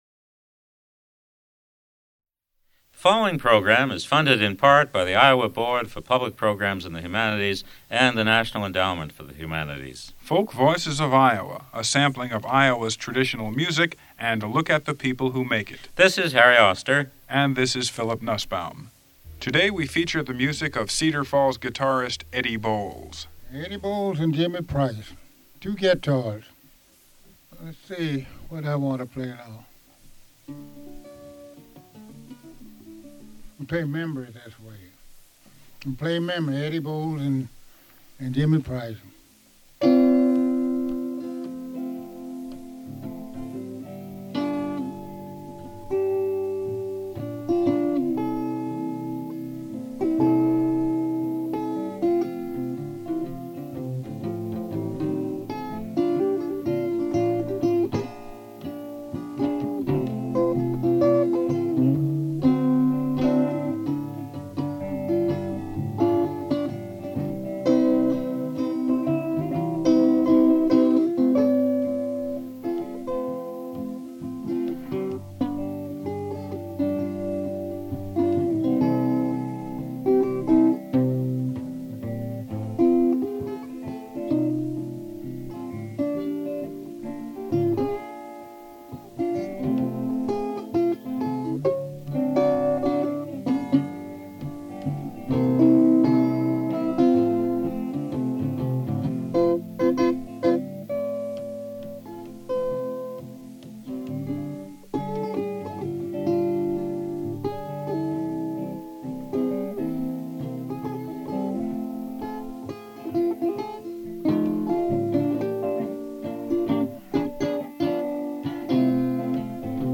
Blues musicians--Iowa--Cedar Falls--Interviews
KUNI Live from Studio One Recordings
Original Format 3M magnetic audio tape (reel)